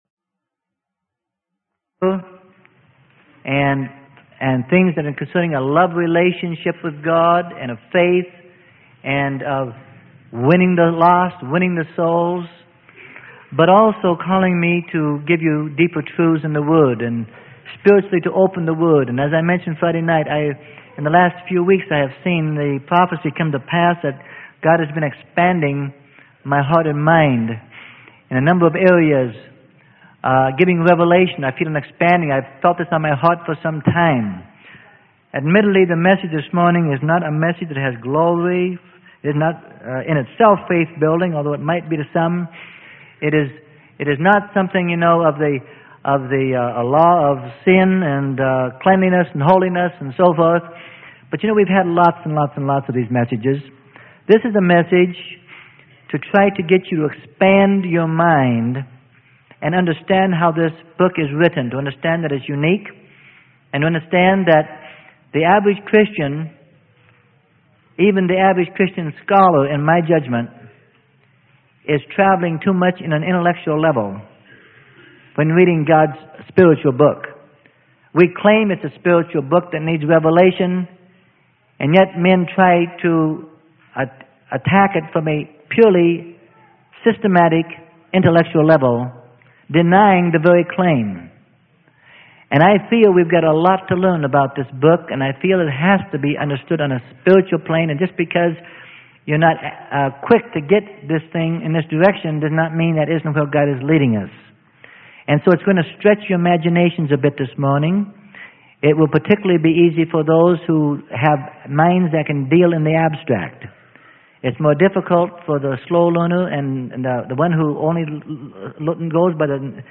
Sermon: Toward Understanding God's Time Frame - Freely Given Online Library